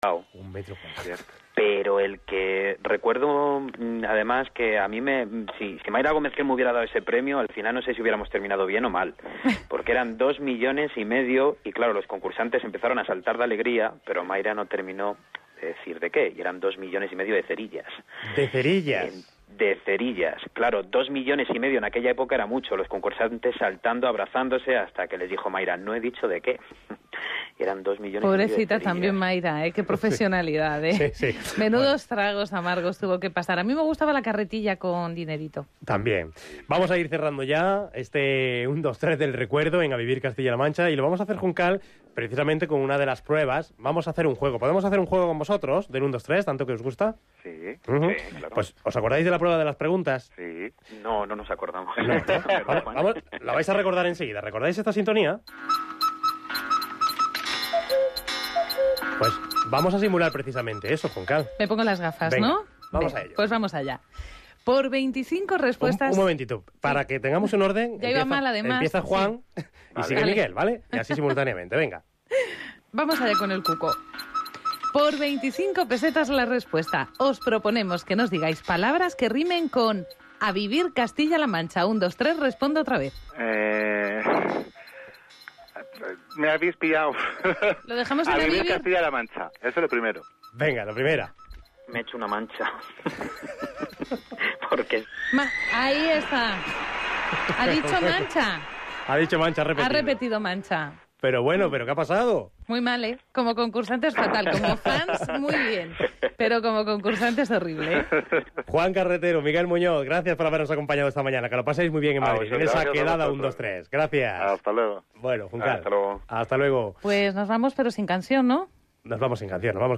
Cuarta parte de la charla